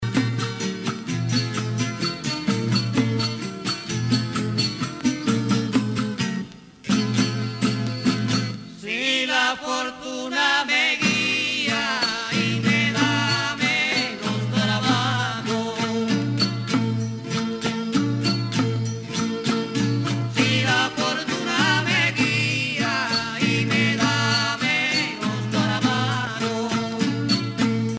Punto en clave
Pièces musicales tirées de la Parranda Tipica Espirituana, Sancti Spiritus, Cuba
Pièce musicale inédite